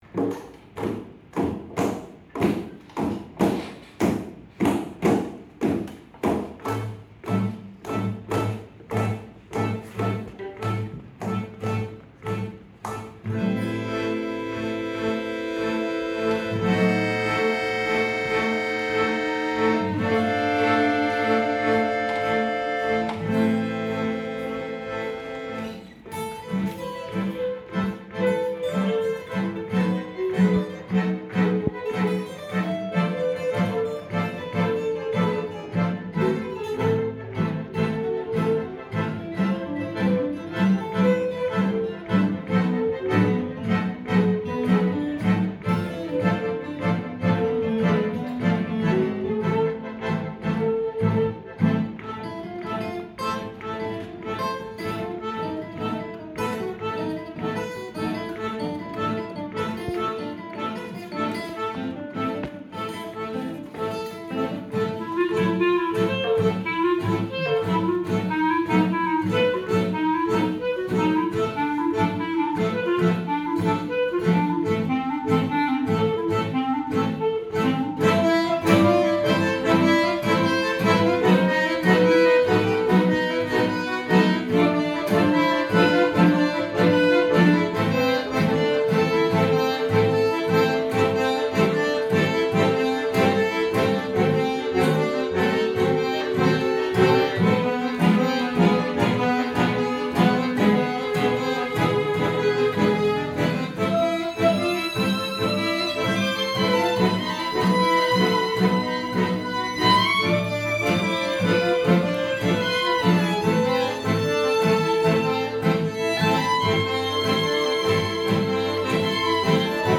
violon, alto, deux guitares classiques, un accordéon et une clarinette en si bémol
guitare classique et improvisations